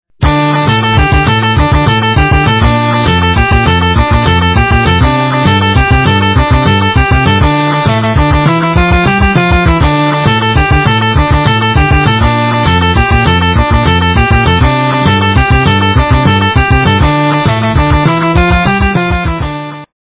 - рок, металл
качество понижено и присутствуют гудки